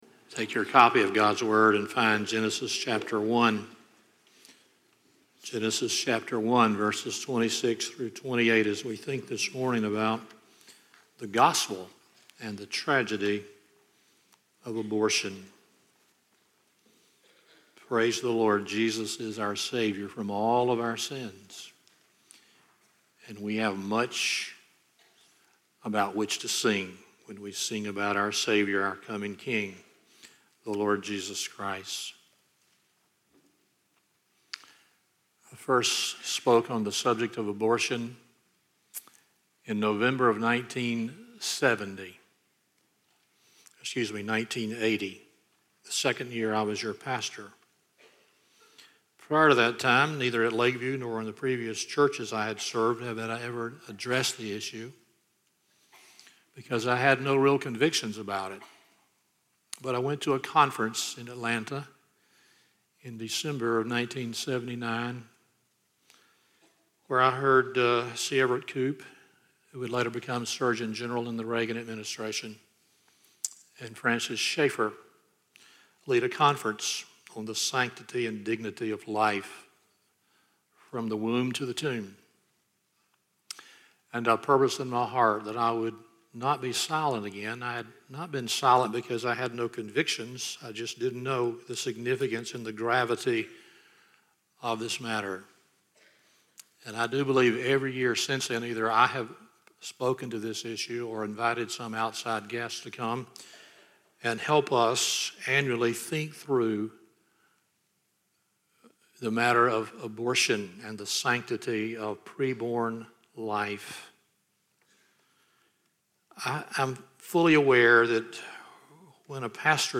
Stand Alone Sermons Passage